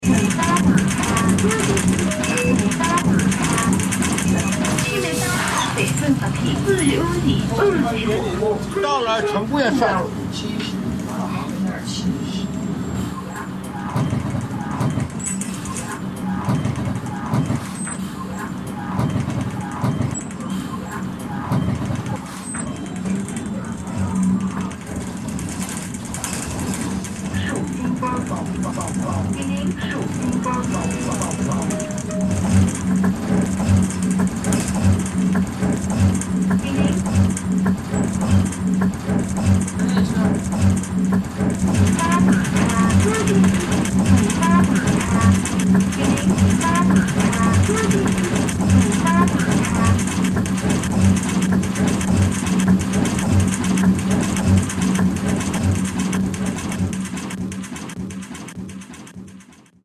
I heard the music of persistence midst decay, driving forward. The bus rattling, heaving and sighing like waves, while a voice cries out, its slogan repeats and even with translation is perplexing.
Everything you hear is an element from the original field recording that has been reorganised, stretched or looped. No additional content has been added.